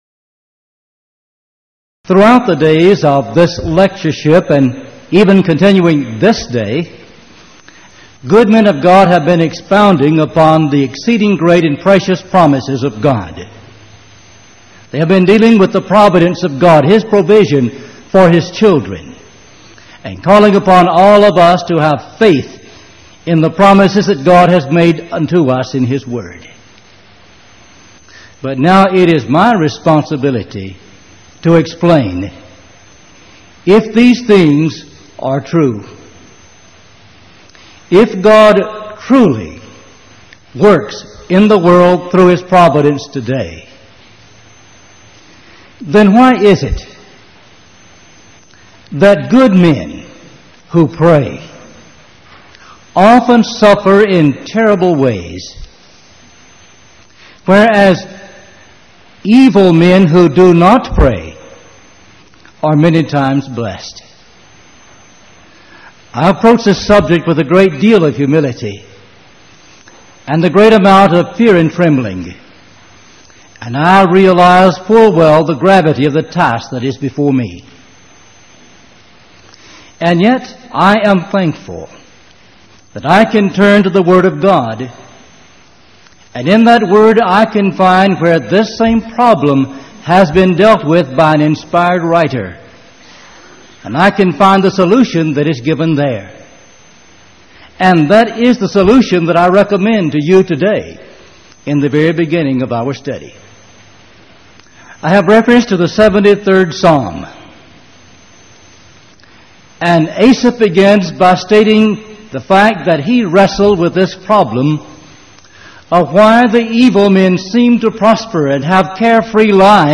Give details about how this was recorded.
Event: 1989 Power Lectures